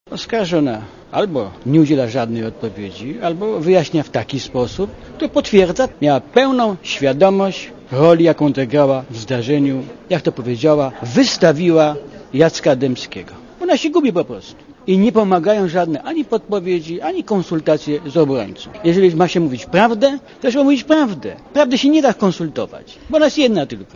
Posłuchaj, co mówi mecenas (96 KB)